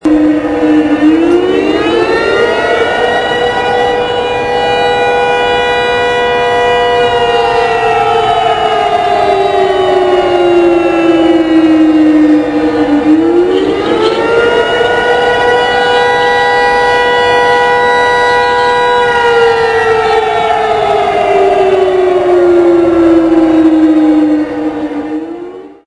Tickets given for successful completion of games can be exchanged for exciting prizes:On September 18th, the entire nation remembers the start of the Japanese invasion in 1931.  At 10:00AM, air raid sirens sound for several minutes (click
Sirens.mp3